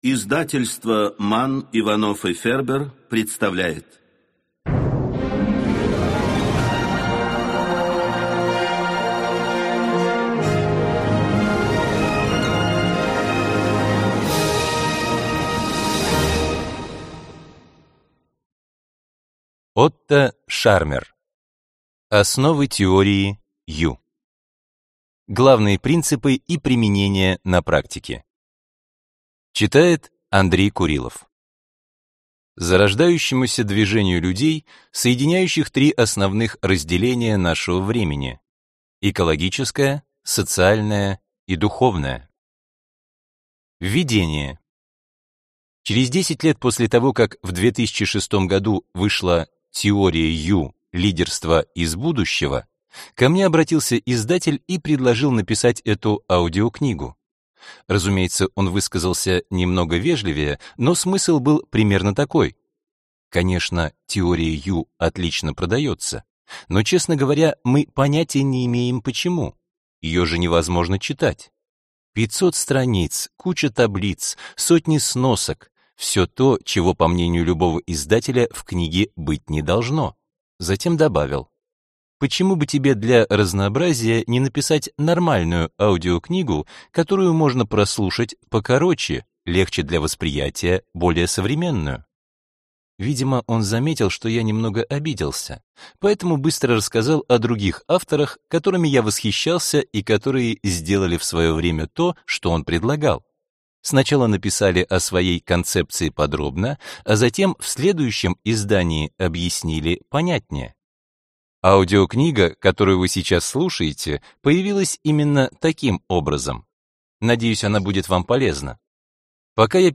Аудиокнига Основы Теории U | Библиотека аудиокниг